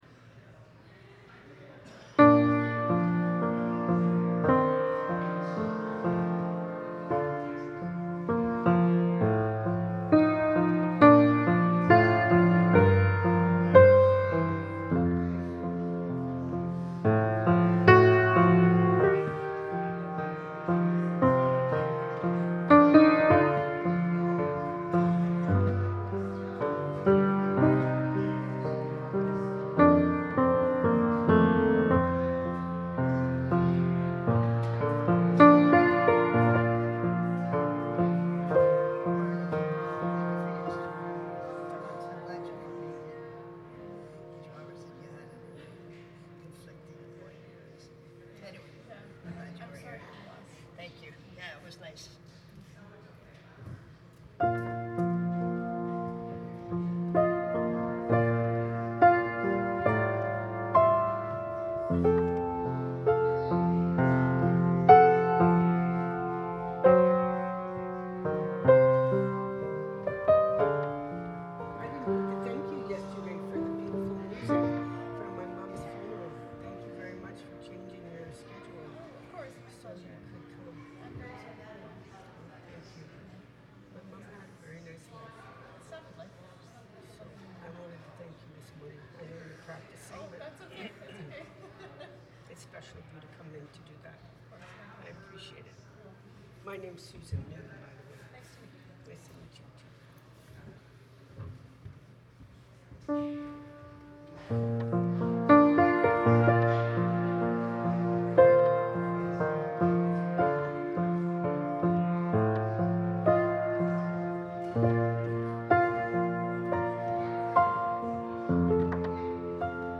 Passage: Psalm 116 Service Type: Sunday Service Scriptures and sermon from St. John’s Presbyterian Church on Sunday